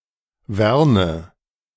Werne an der Lippe (German pronunciation: [ˈvɛʁnə]